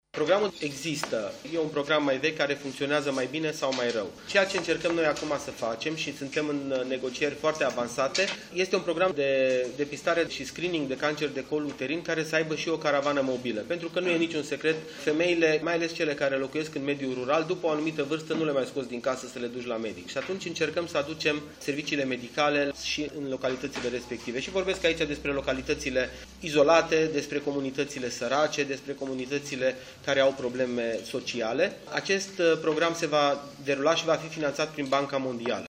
Ministrul Sănătăţii, doctorul Florian Bodog a vorbit despre acest program, astăzi, la Piteşti: